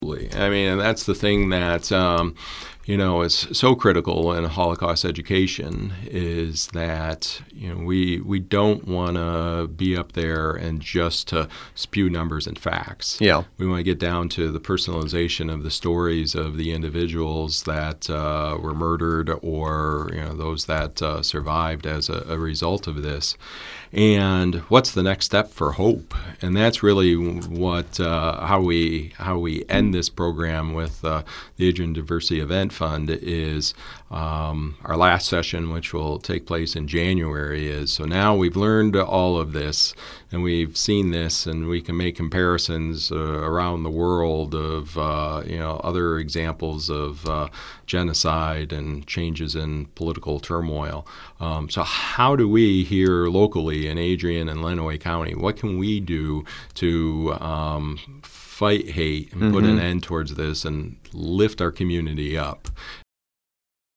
That was City Commissioner Bob Behnke.